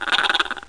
1 channel
scraper2.mp3